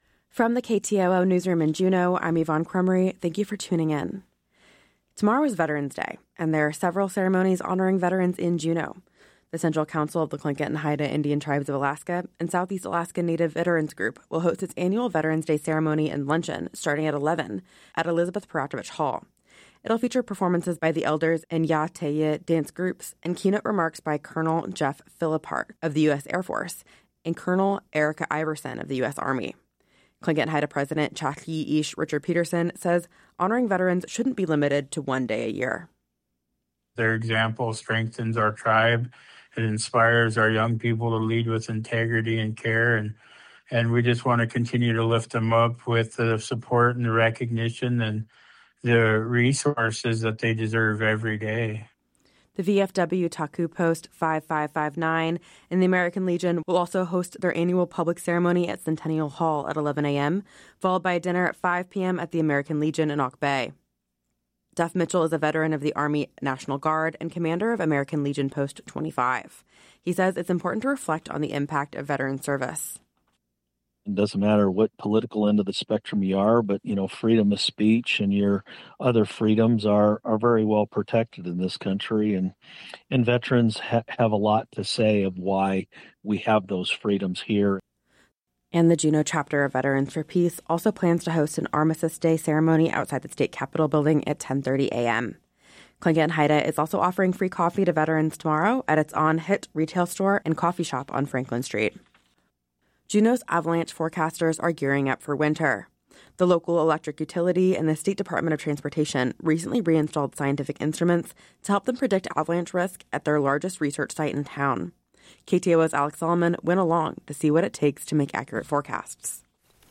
Newscast – Monday, Nov. 10, 2025